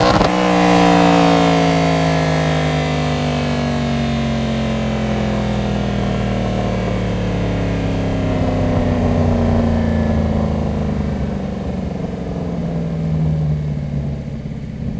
rr3-assets/files/.depot/audio/Vehicles/v8_13/v8_13_decel.wav
v8_13_decel.wav